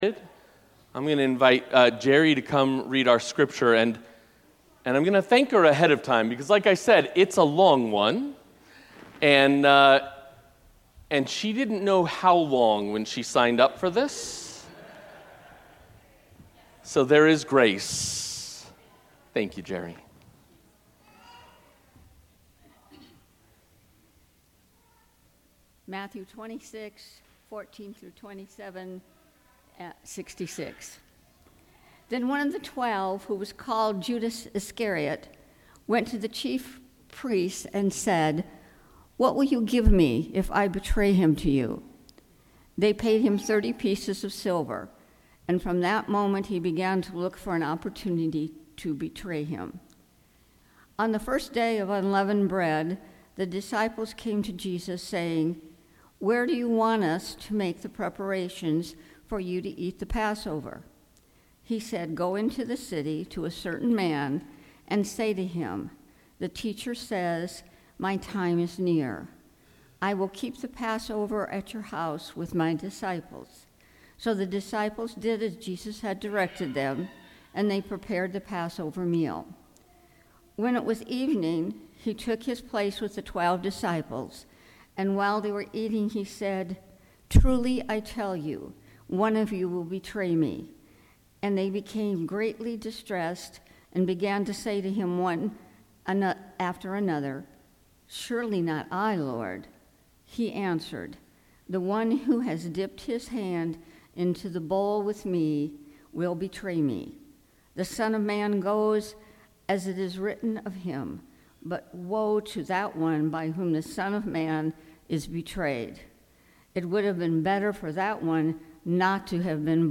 Sermons | Broadway United Methodist Church